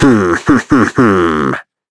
Shakmeh-Vox-Laugh.wav